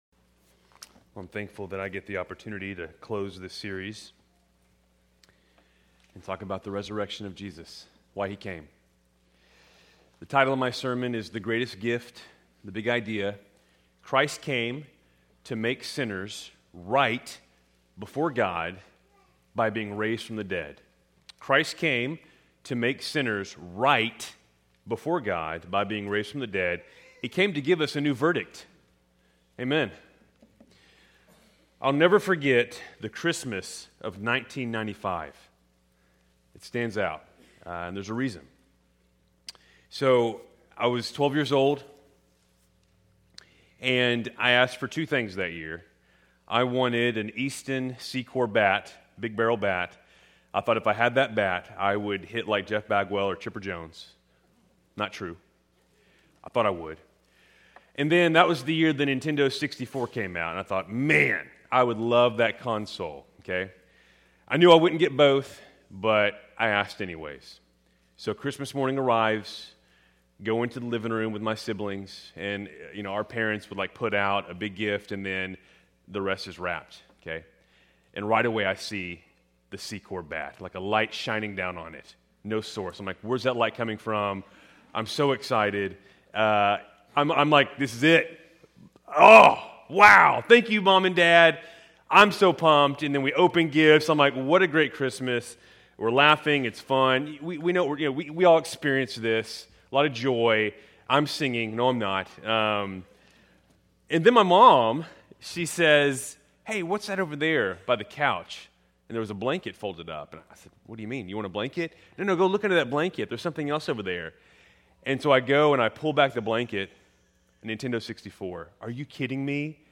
Keltys Worship Service, December 21, 2025